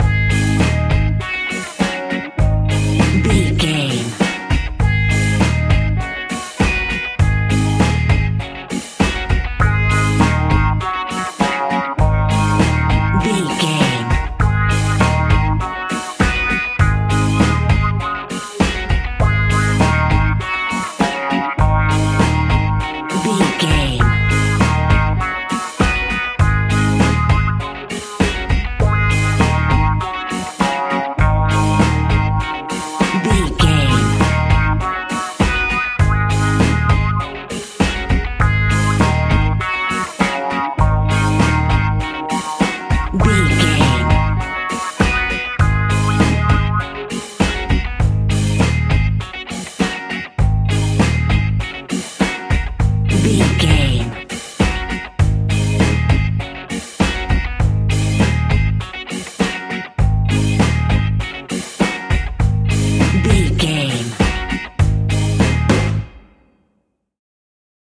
Classic reggae music with that skank bounce reggae feeling.
Uplifting
Ionian/Major
A♭
dub
laid back
chilled
off beat
drums
skank guitar
hammond organ
transistor guitar
percussion
horns